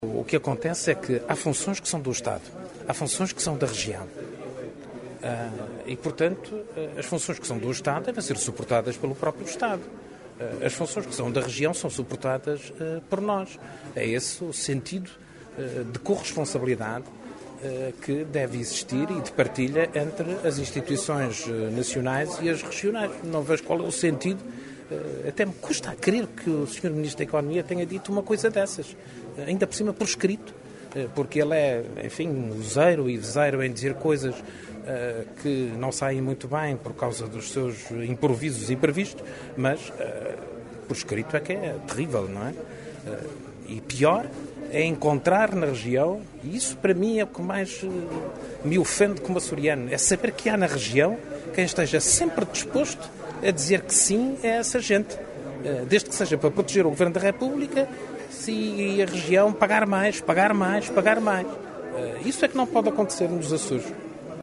Em declarações aos jornalistas hoje na Horta, o Presidente do Governo referiu que a líder do PSD-Açores tem a “singularidade” de, “ao mesmo tempo que está sempre a dizer que a Região está numa situação financeira muito difícil, ao mesmo tempo está sempre a prometer coisas que custam dinheiro à Região Autónoma dos Açores”.